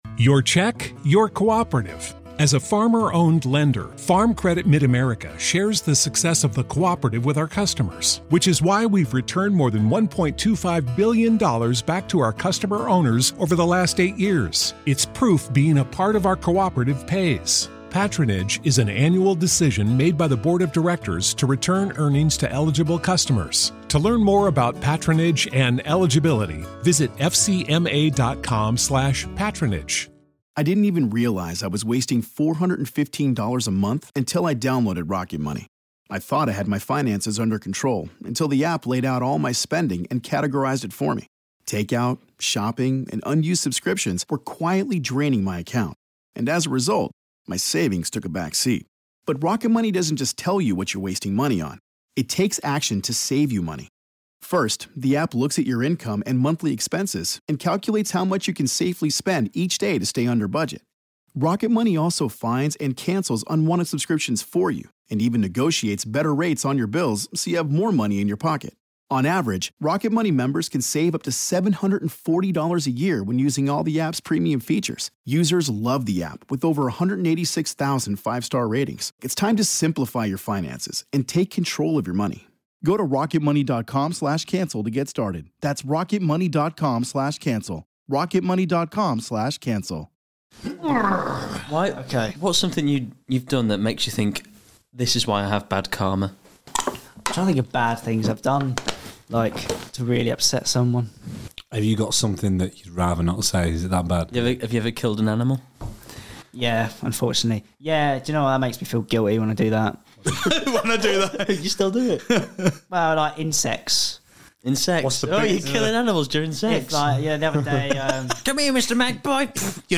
This week we're talking about police, parenting and pulling in Ibiza. We are also joined by the 2nd best Elvis impersonator in the world